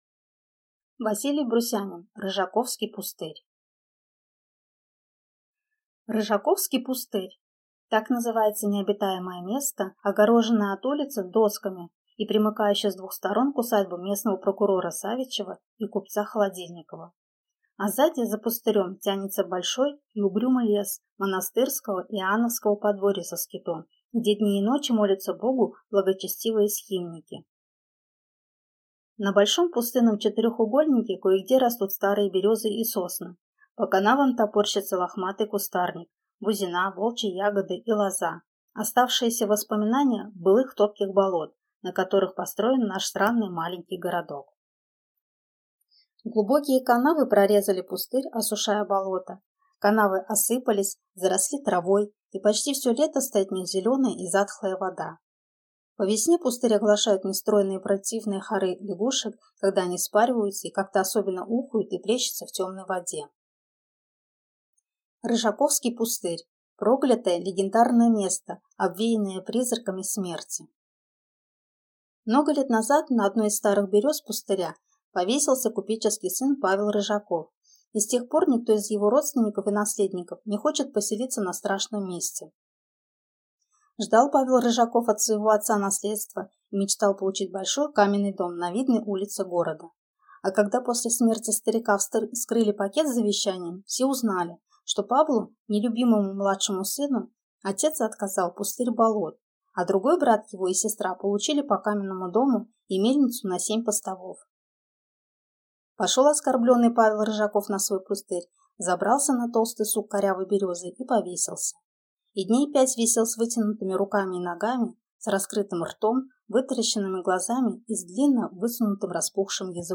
Аудиокнига Рыжаковский пустырь | Библиотека аудиокниг